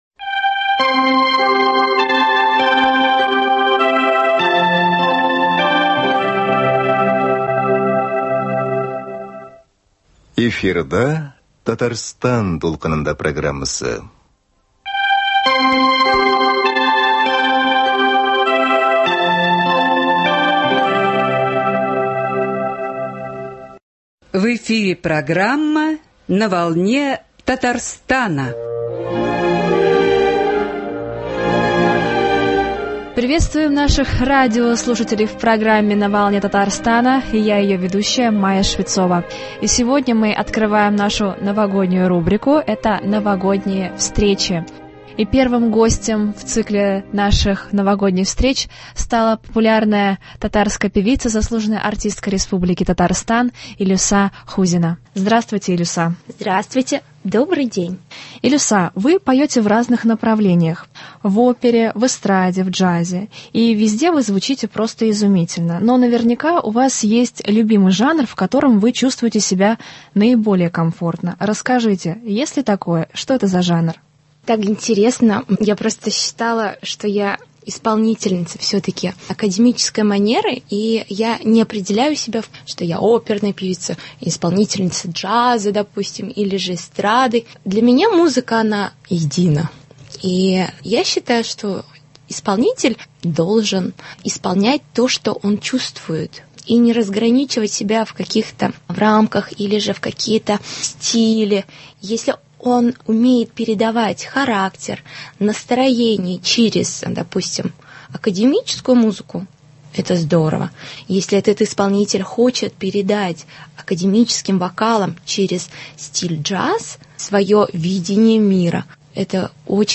Беседа с заслуженной артисткой Республики Татарстан